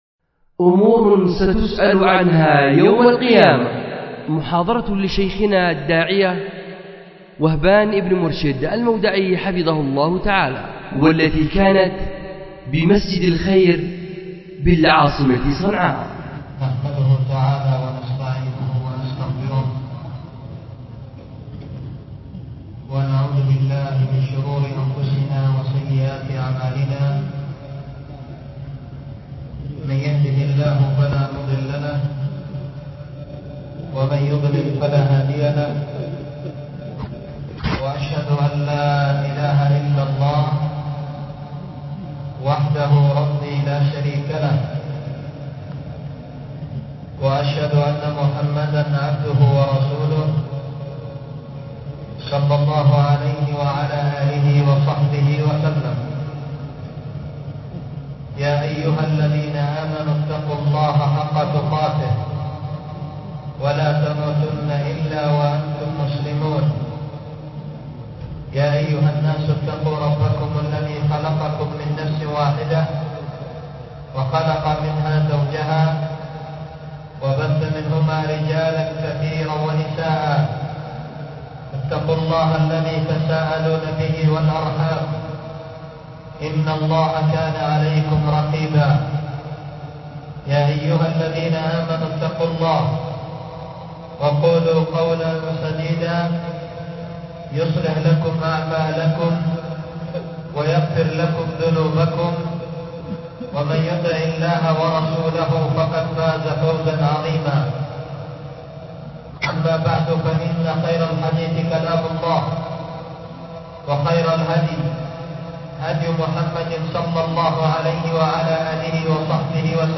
أمور ستسأل عنها يوم القيامة ـ محاضرة بمسجد الخير بصنعاء
أُلقيت بمسجد الخير ـ اليمن ـ صنعاء